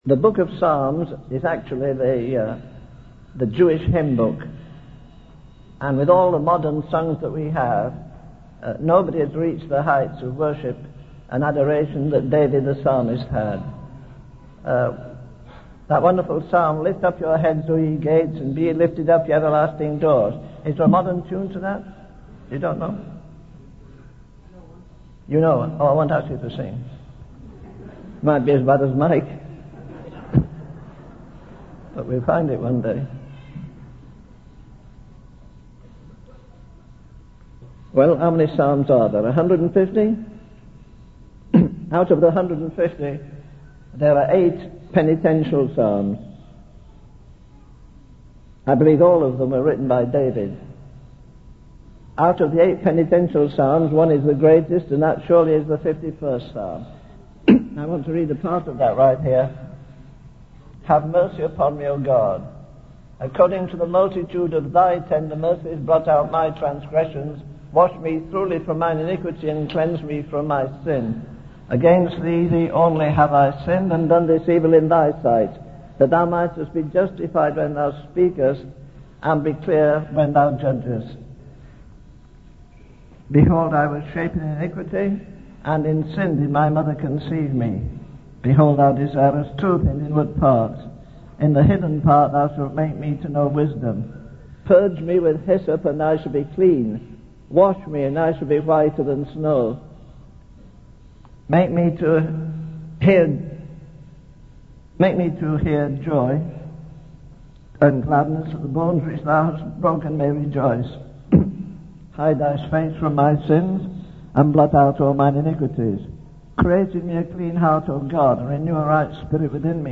In this sermon, the preacher emphasizes the importance of preparing for the final judgment.